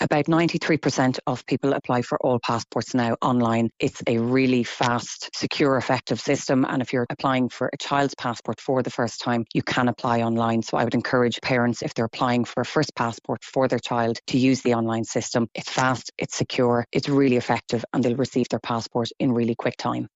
Helen McEntee, Foreign Affairs Minister says the online system is fast and secure.